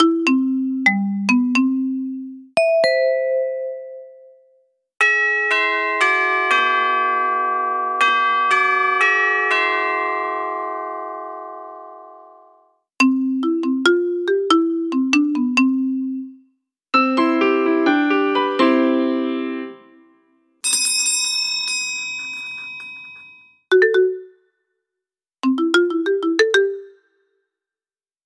kit carillon / émission de son sans fil / portée: 200m / volume sonore: réglable jusqu'à 90 dB / 8 mélodies / couleur: blanc / contenu: carillon sans fil et bouton-poussoir de sonnette DCP911 / mode de fonctionnement: batterie 4xLR14 1,5 V/connexion secteur via micro-USB 5 V, max. 2 A
• changement de sonnerie
Melodien_128333.mp3